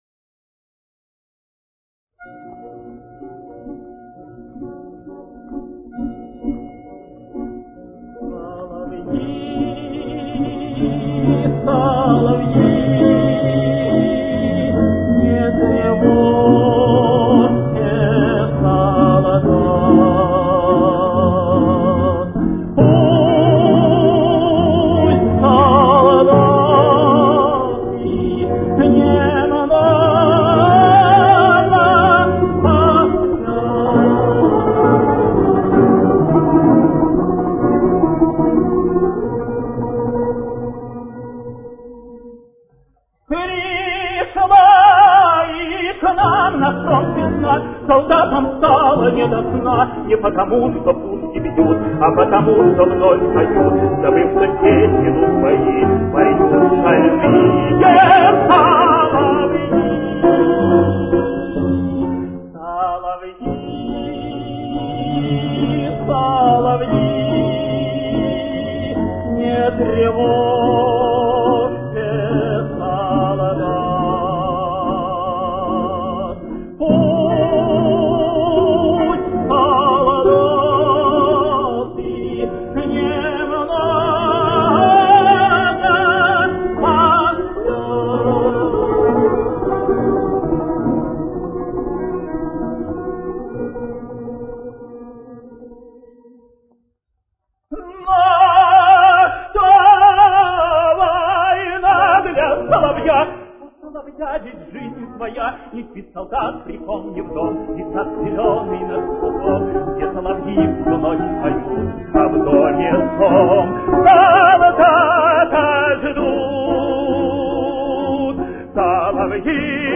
Си-бемоль минор.